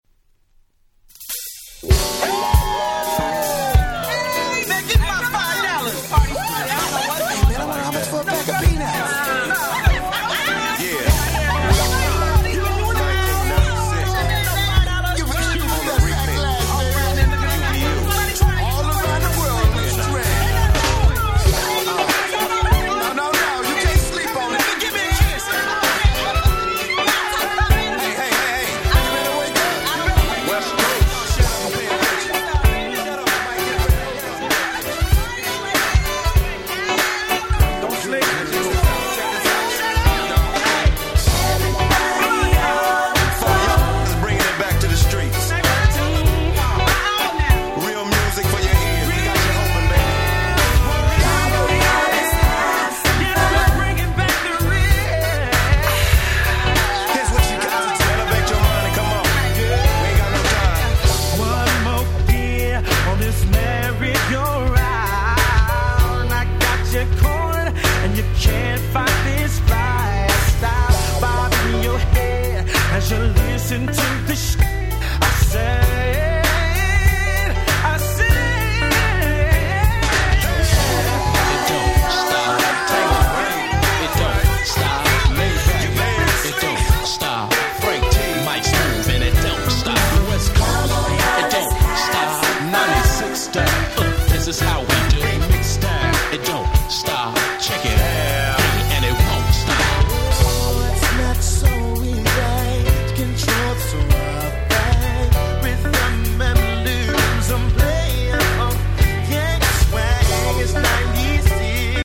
95' Nice R&B !!
詳細不明の男性Vocal Unit。
内容はSaxのメロディーも心地良いオシャレな1曲でMixの1曲目なんかに使うと非常に映えそうです！
ウブ プロモオンリー NJS ニュージャックスウィング New Jack Swing 90's